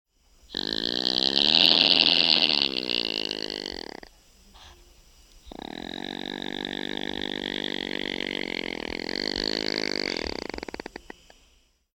Virginia Opossum
Voice
Virginia opossums produce clicking noises when they are trying to attract mates and hiss or growl when they feel threated. Baby opossums make noises that sound like sneezing when trying to get their parents' attention.
virginia-opossum-call.mp3